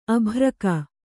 ♪ abhraka